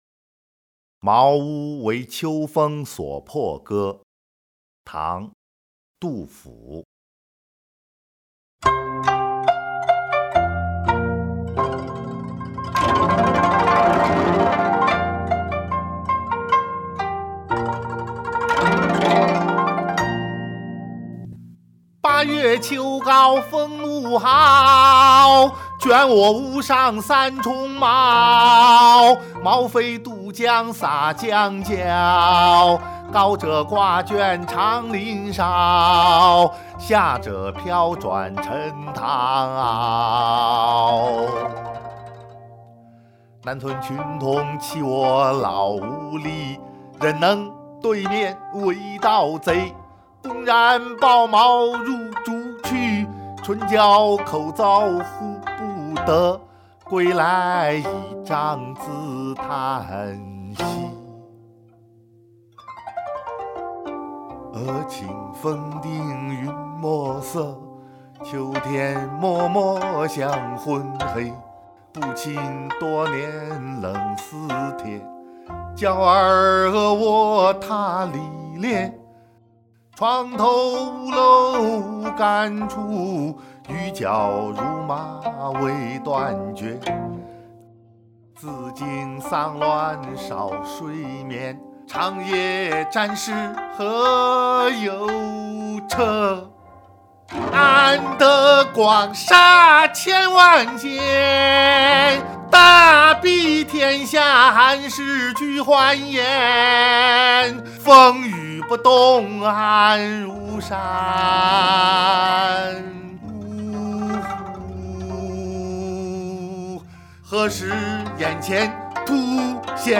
《茅屋为秋风所破歌》（吟咏）［唐］杜　甫